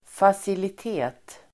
Ladda ner uttalet
facilitet substantiv, amenity , facility Uttal: [fasilit'e:t]